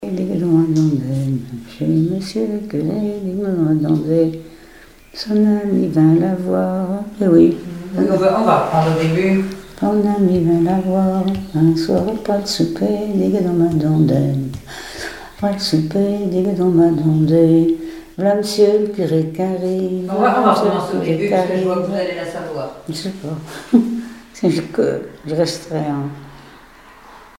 Menomblet
Genre laisse
Pièce musicale inédite